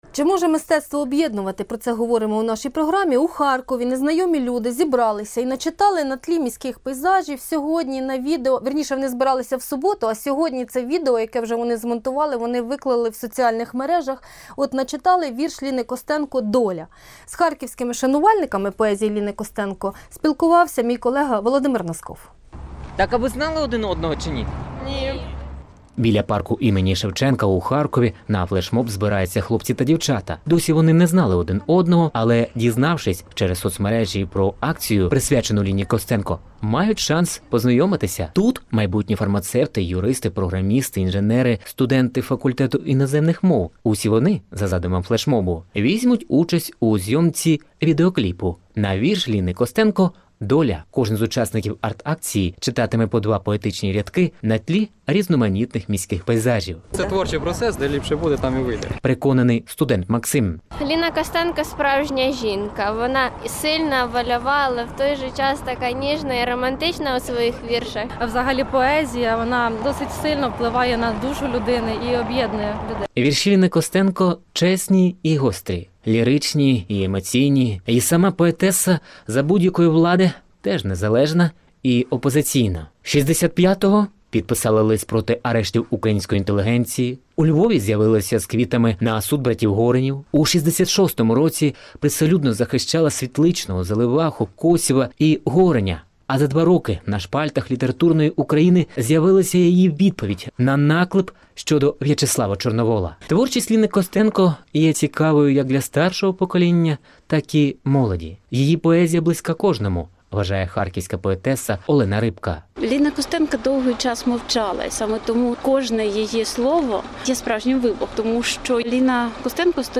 Молодь різних регіонів України читає вірші Ліни Костенко